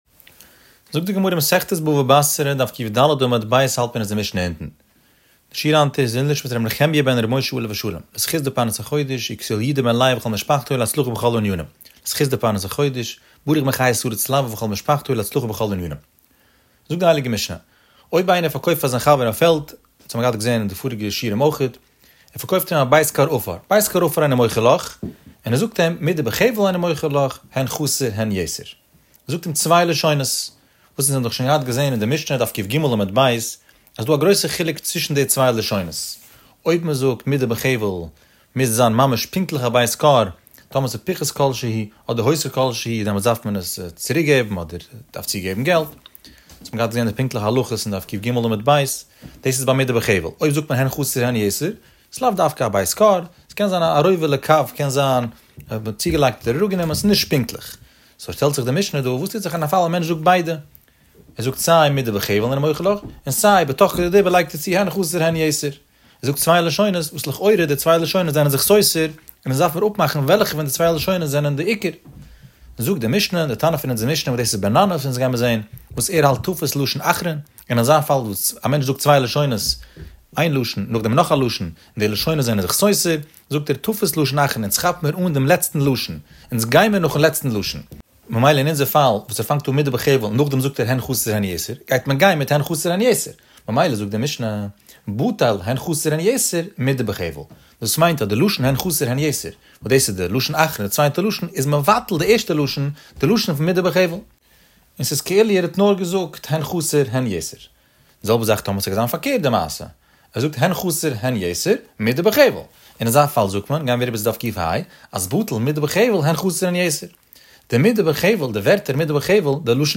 The daily daf shiur has over 15,000 daily listeners.